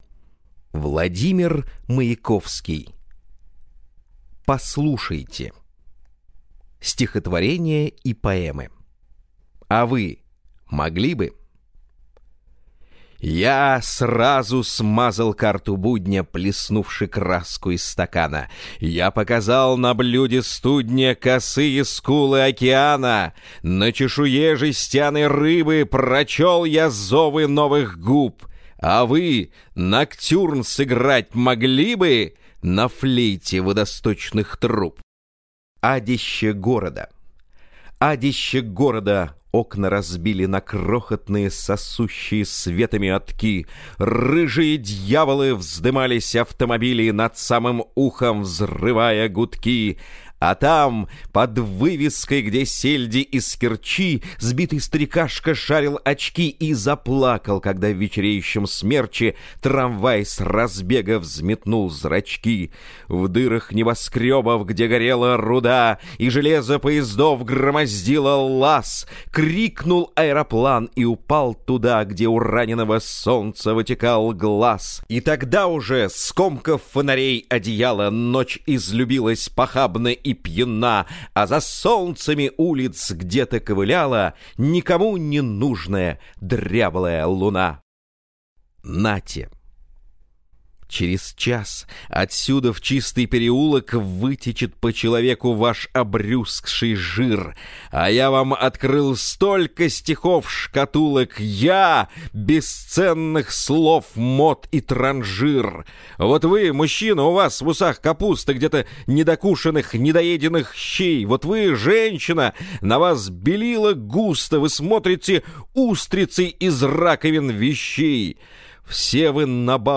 Аудиокнига Послушайте! (сборник) | Библиотека аудиокниг